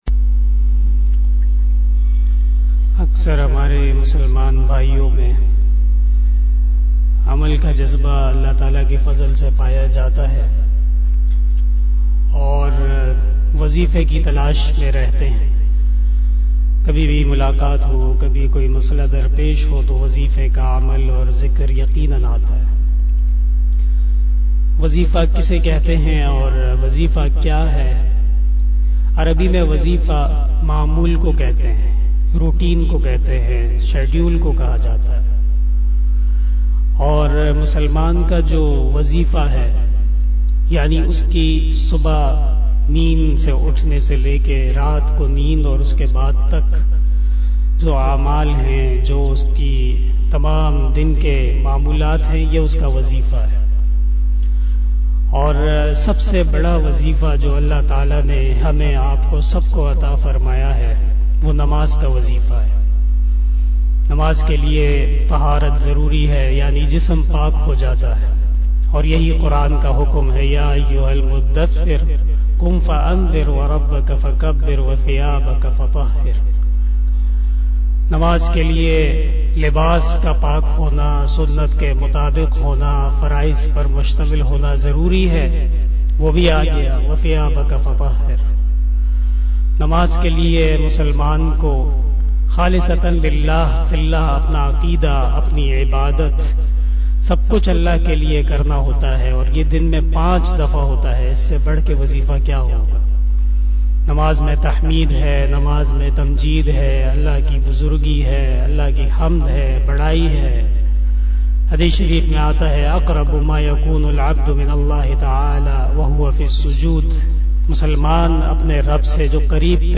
081 After Asar Namaz Bayan 01 Decamber 2021 (26 Rabi us Sani 1443HJ) wednesday